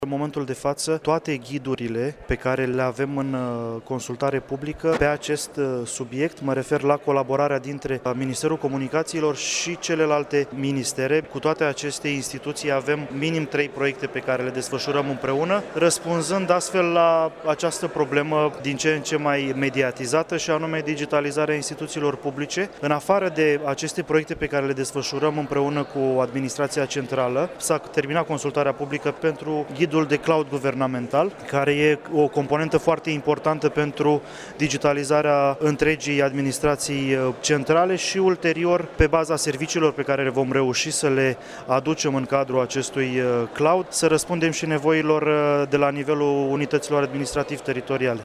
Ministrul Comunicaţiilor şi Societăţii Informaţionale, Bogdan Cojocaru, a anunțat astăzi, la Iași, că reprezentanţii Guvernului analizează datele din consultarea publică în vederea întocmirii ghidurilor guvernamentale pentru digitalizarea administraţiei publice din România.